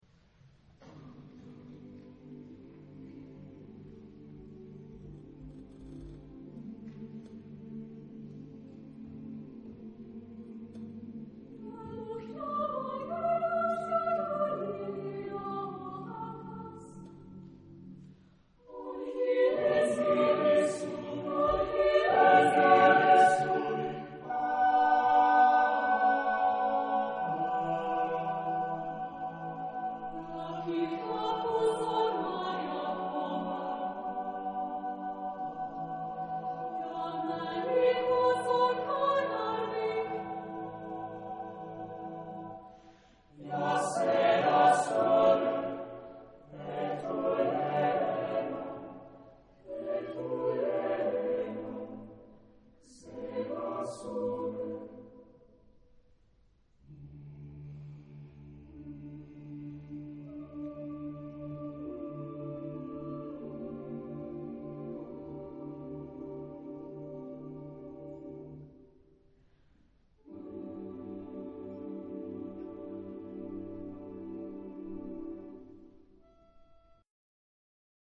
Genre-Style-Form: Contemporary ; Partsong ; Secular
Type of Choir: SSAATTBB  (8 mixed voices )
Tonality: G major